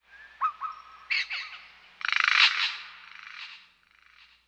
bird_echo2.wav